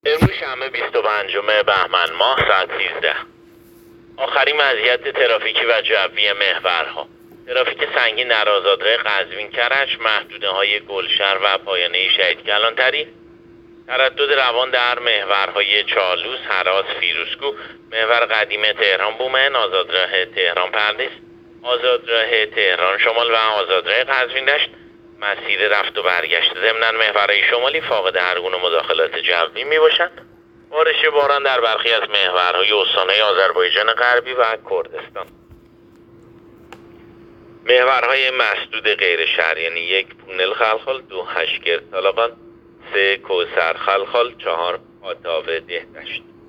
گزارش رادیو اینترنتی از آخرین وضعیت ترافیکی جاده‌ها ساعت ۱۳ بیست و پنجم بهمن؛